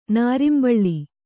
♪ nārimbaḷḷi